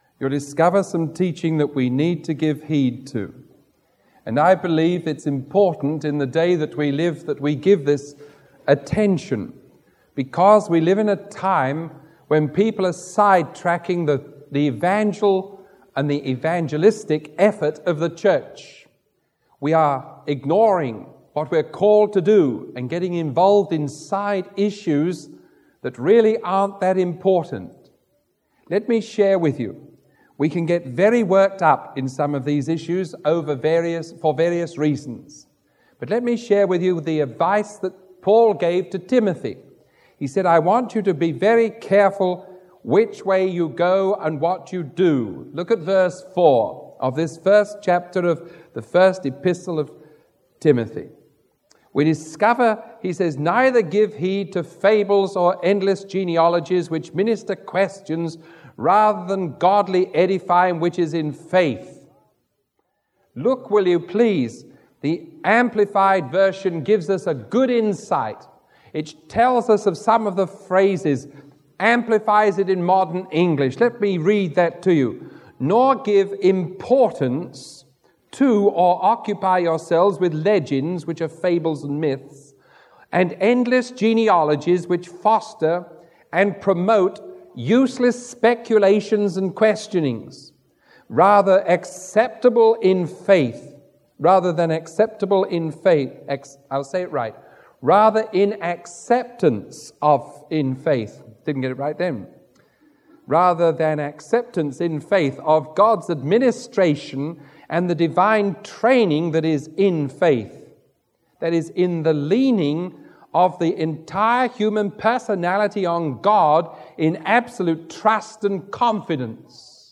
Sermon 0513A recorded on March 21